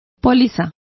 Complete with pronunciation of the translation of policy.